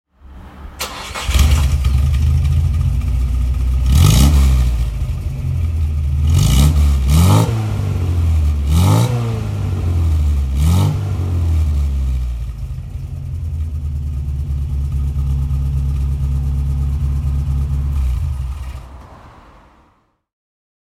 Datsun 1600 Sports (1968) - Starten und Leerlauf
Datsun_Fairlady.mp3